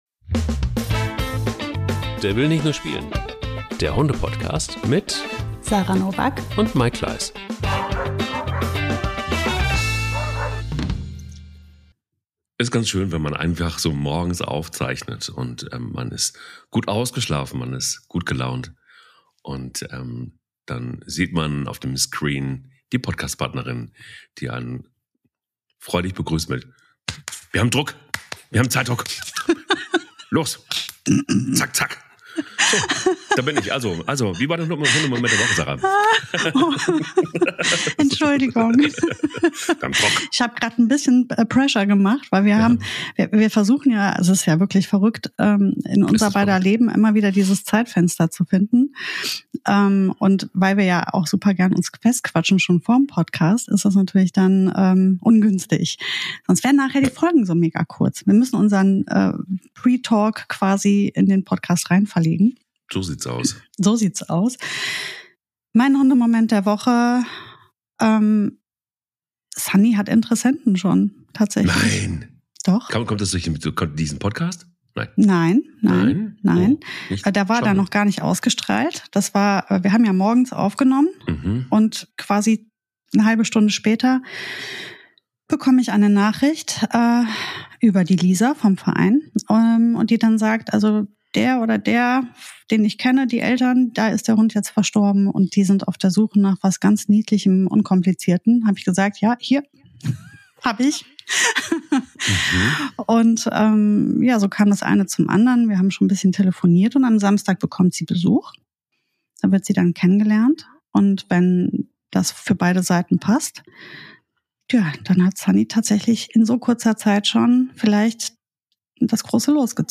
Denn irgendwie geht es auch darum, Abschied zu nehmen. Demenz bei Hunden ist der Vorbote des Abschieds, und genau das tut so unfassbar weh. Eine sehr emotionale Folge, das kann man schon direkt am Anfang hören.